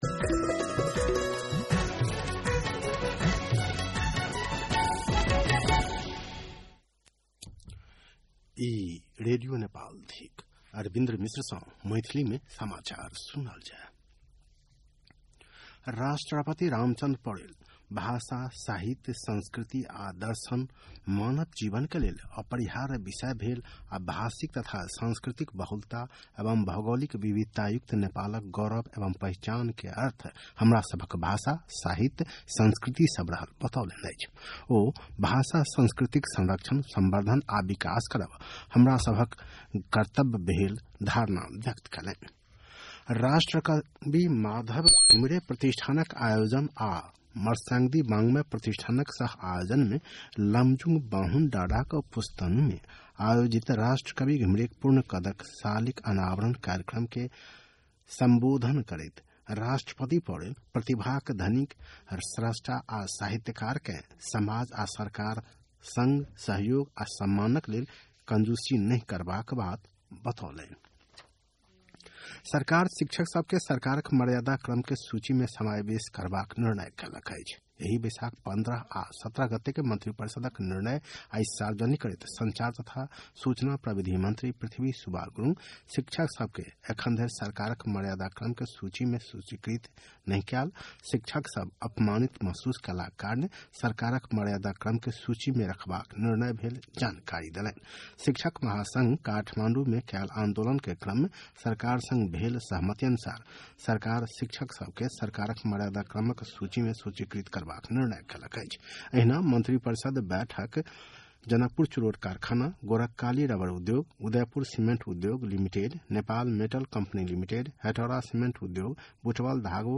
मैथिली भाषामा समाचार : २१ वैशाख , २०८२
6.-pm-maithali-news.mp3